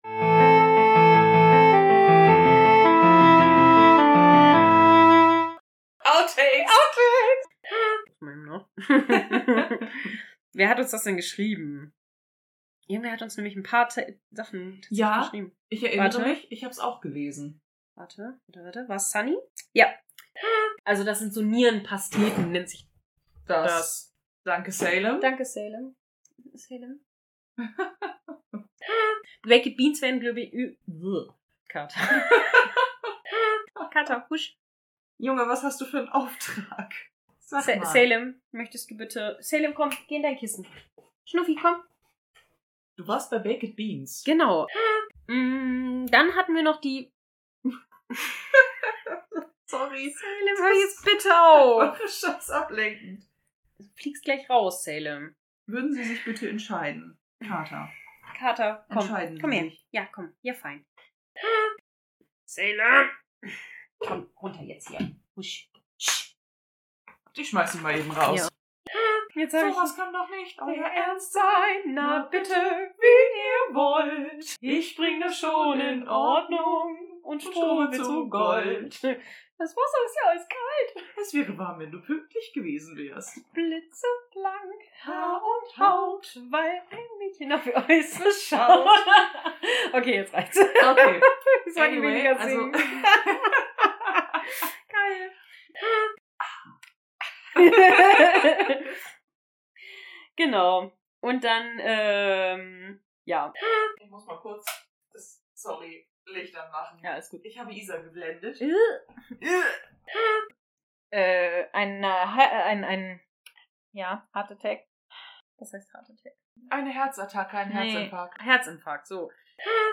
Diesesmal eine recht kurze Outtakesfolge. Es wird gelacht,
gesungen und es gibt Cattrouble. Viel Spaß